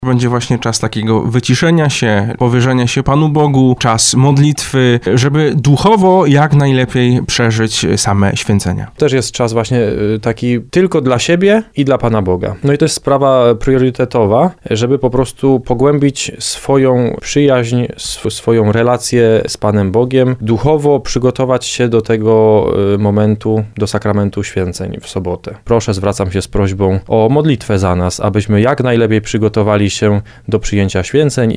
mówią diakoni